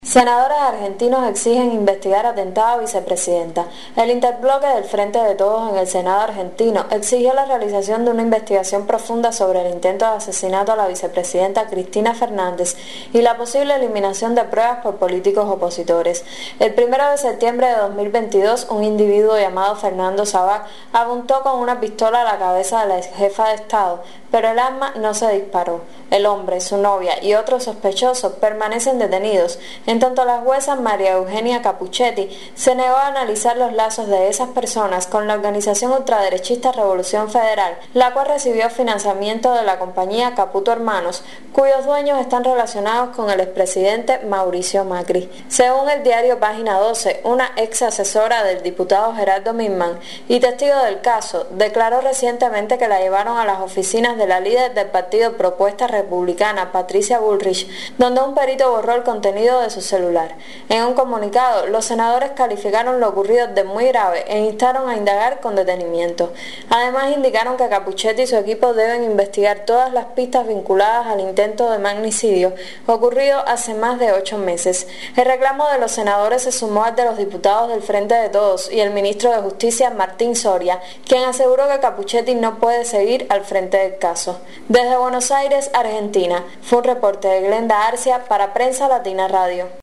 corresponsal en Argentina